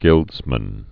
(gĭldzmən)